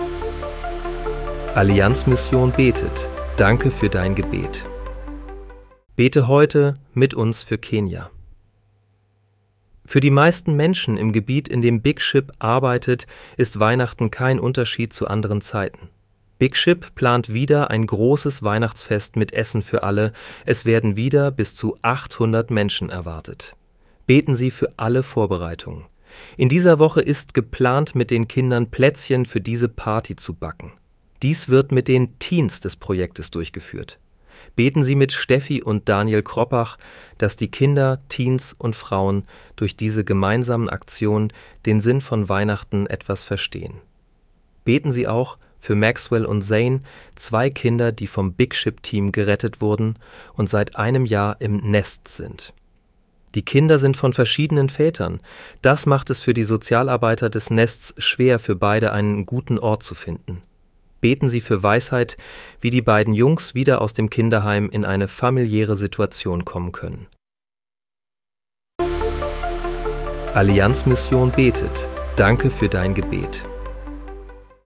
Bete am 17. Dezember 2025 mit uns für Kenia. (KI-generiert mit der